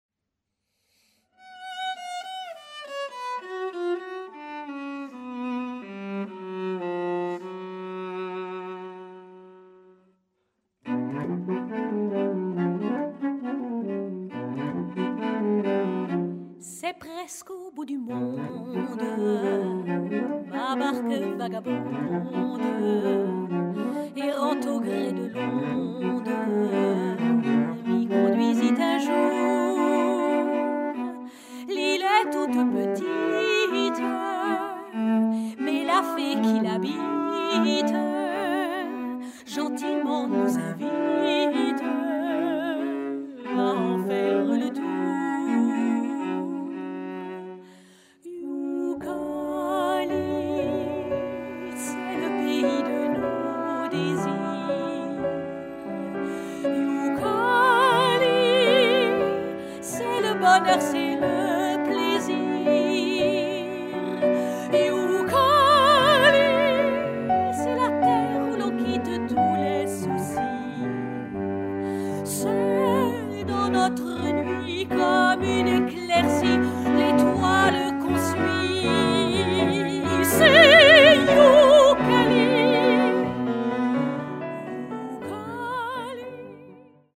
chant (soprano)
saxophone(s)
piano, chant, arrangement
alto, accordéon,arrangement